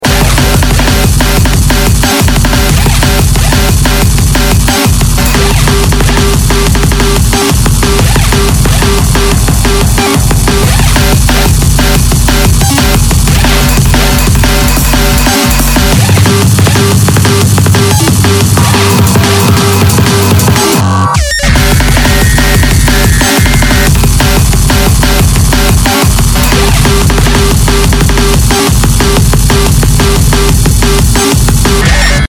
Drum'n'bass